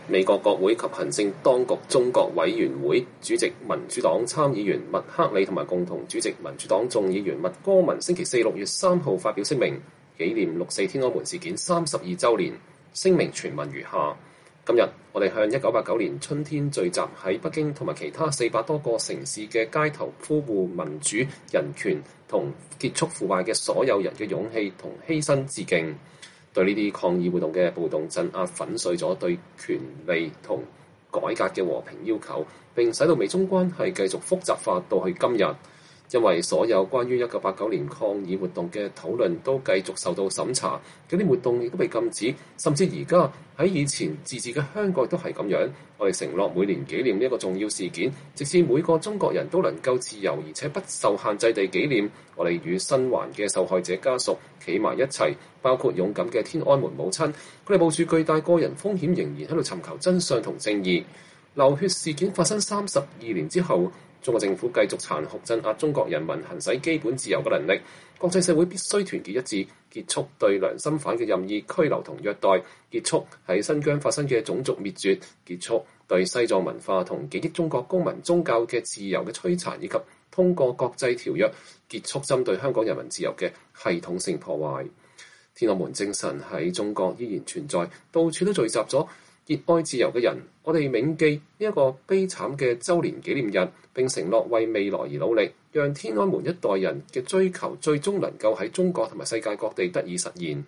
在參議院全院即將無異議通過《香港人權與民主法案》之際，俄勒岡州民主黨聯邦參議員默克里(Jeff Merkley)在院會發表演說。